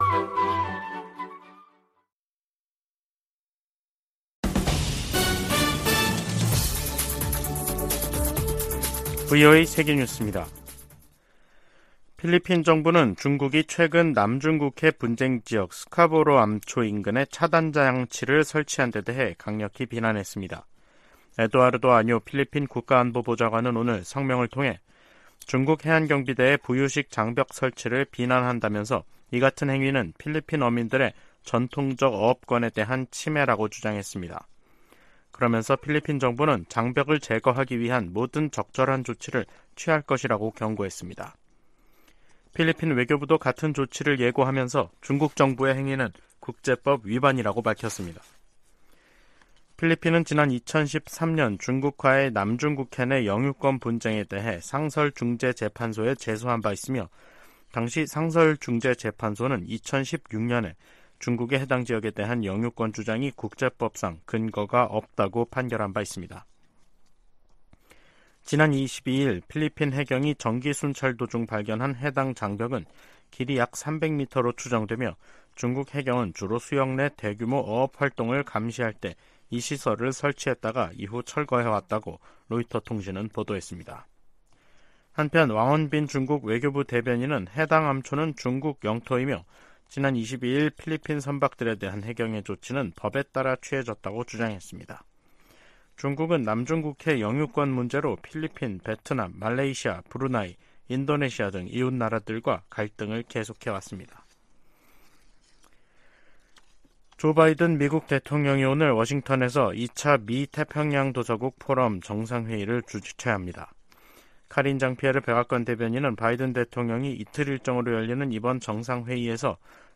VOA 한국어 간판 뉴스 프로그램 '뉴스 투데이', 2023년 9월 25일 2부 방송입니다. 조 바이든 미국 대통령은 러시아가 우크라이나의 평화를 가로막고 있다면서 이란과 북한으로부터 더 많은 무기를 얻으려 하고 있다고 비판했습니다. 시진핑 중국 국가주석이 한국 방문 의사를 밝히고 관계 개선 의지를 보였습니다. 미국, 일본, 인도, 호주 4개국이 유엔 회원국에 북한과 무기 거래를 하지 말 것을 촉구했습니다.